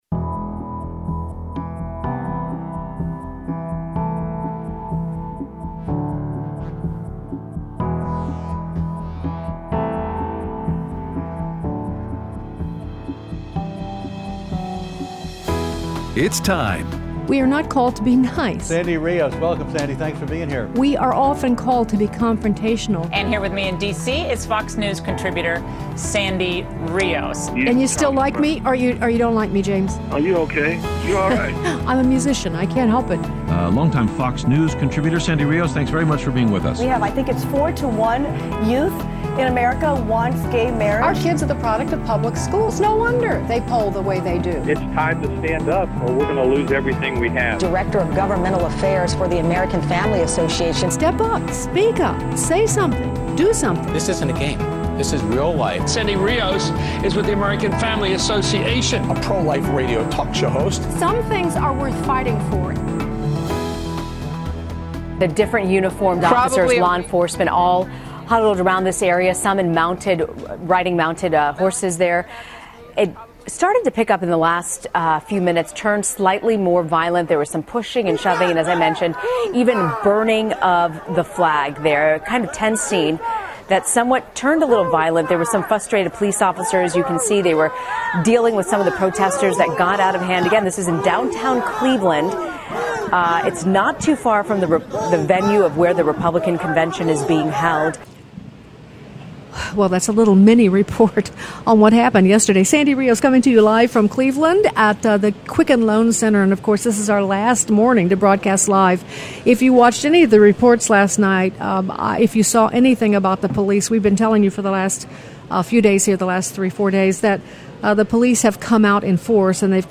Live at the RNC Day 4